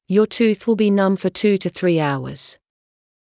ﾕｱ ﾄｩｰｽ ｳｨﾙ ﾋﾞｰ ﾅﾑ ﾌｫｰ ﾄｩ ﾄｩ ｽﾘｰ ｱﾜｰｽﾞ